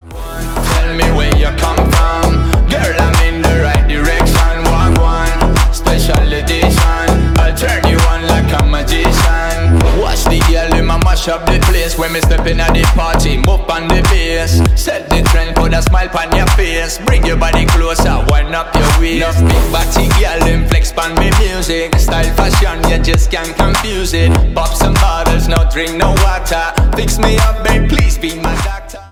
Поп Музыка # Танцевальные
клубные # громкие